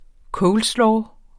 Udtale [ ˈkɔwlˌslɒː ]